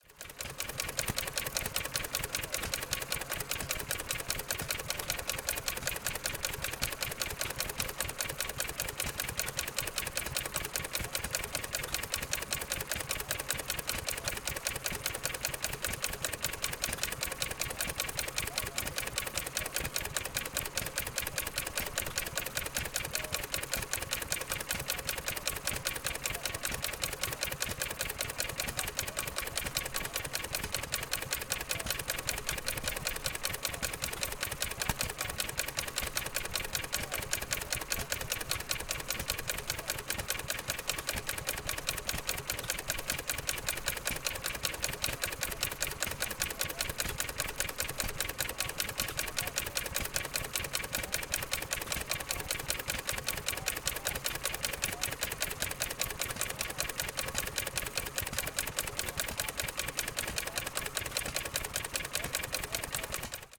JC Sound - Early Gasoline Engines
EGEngines_wico_23-19_fastchugging.ogg